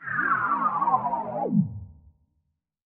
TC3Transition1.wav